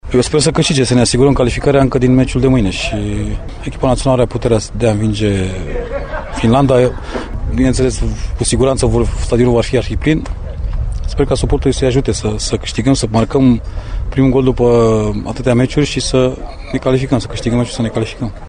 Fostul internaţional Claudiu Niculescu, selecţionat de opt ori în prima reprezentativă a României, speră ca emulaţia din jurul meciului cu Finlanda să ducă la un succes al tricolorilor